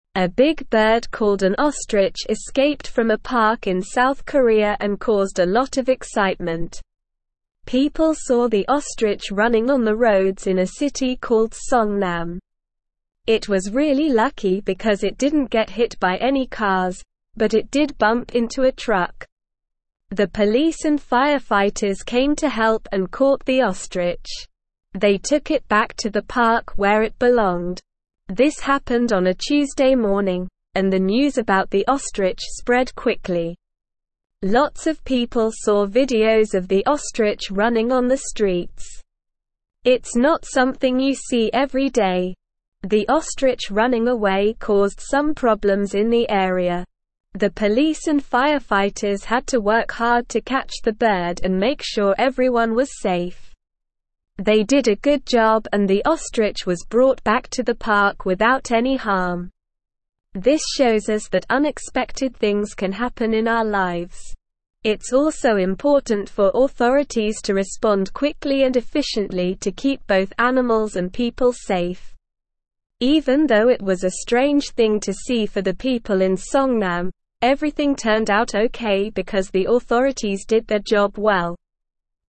Slow
English-Newsroom-Upper-Intermediate-SLOW-Reading-Escaped-ostrich-causes-chaos-on-roads-in-South-Korea.mp3